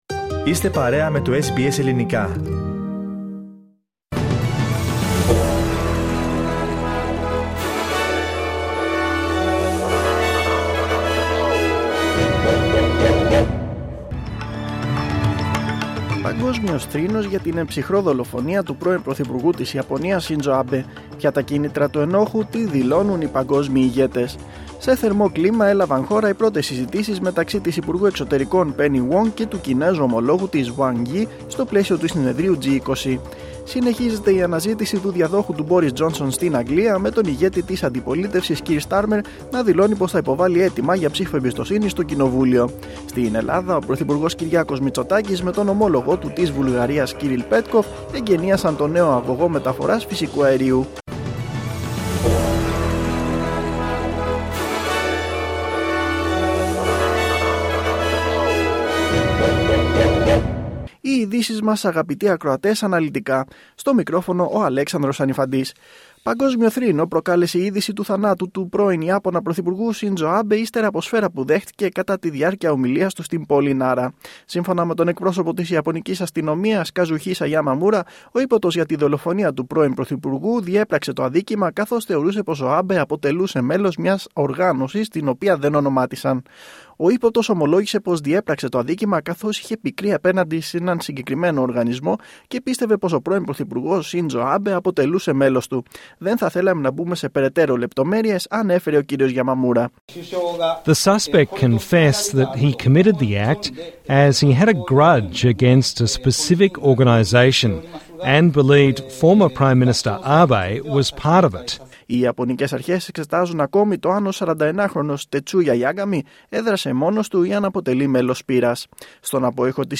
Δελτίο Ειδήσεων Σάββατο 9.7.2022